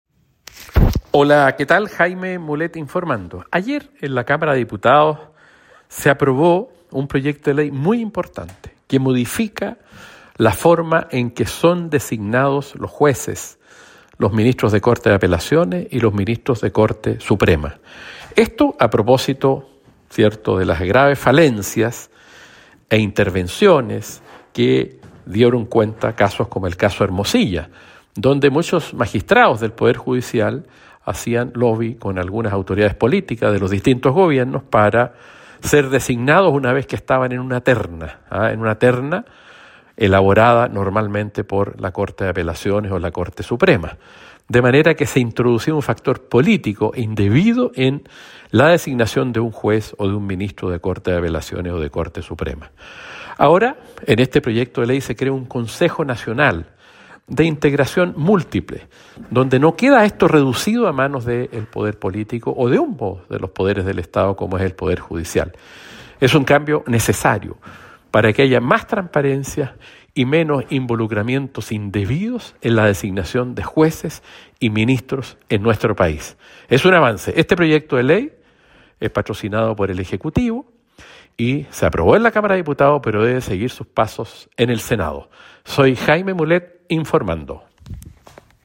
Audio Diputado Jaime Mulet informando / Martes 22 de julio 2025